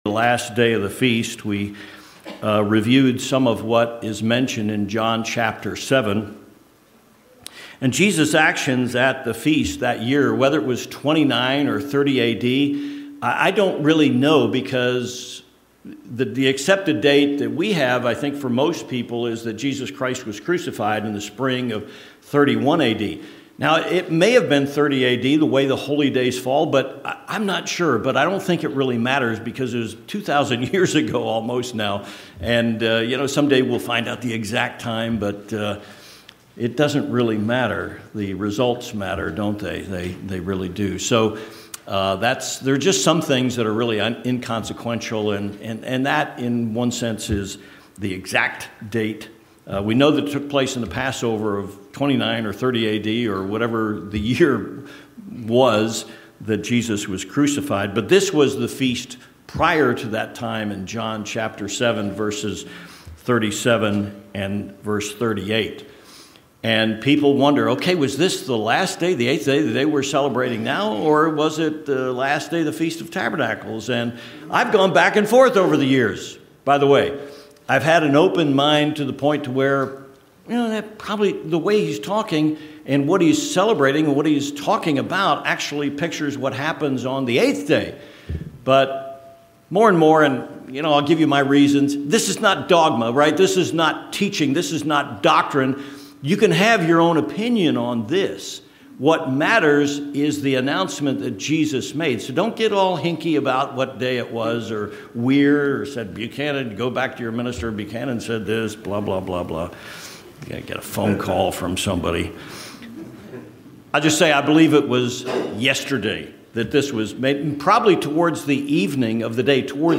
This sermon explores the three fold aspect of The Last Day; The Great Resurrection, the Destruction of the Wicked, and humanity's bridge into Eternity with God.